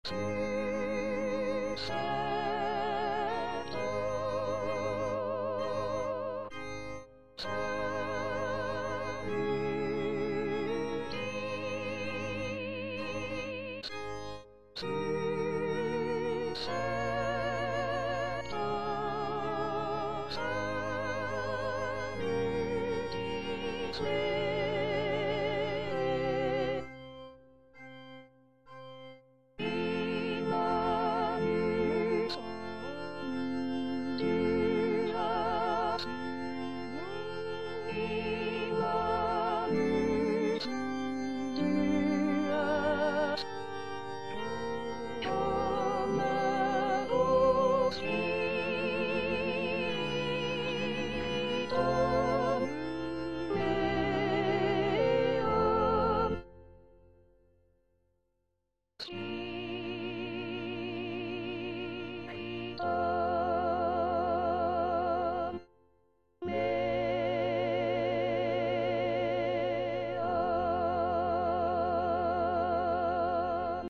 Parole 7: Pater, in manus tuas        Prononciation gallicane (à la française)